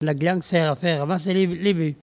Elle provient de Saint-Urbain.
Catégorie Locution ( parler, expression, langue,... )